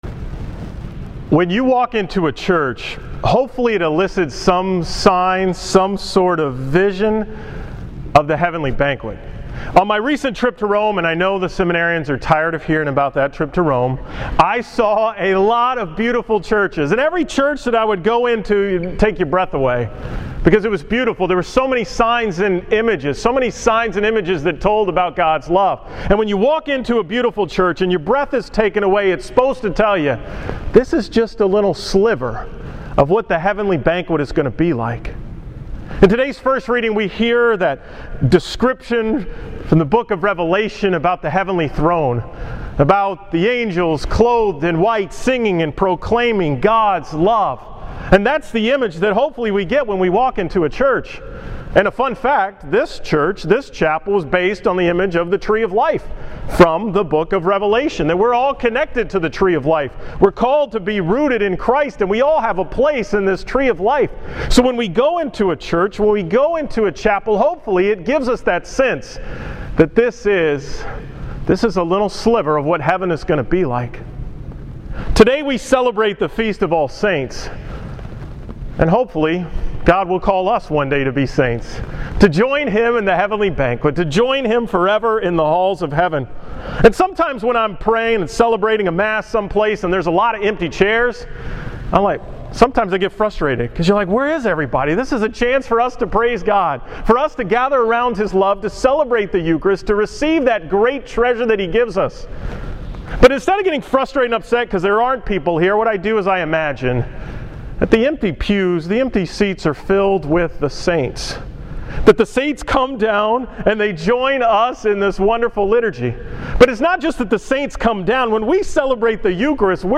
From the 9 am Mass at St. Mary’s Seminary on November 1st, 2014
Category: 2014 Homilies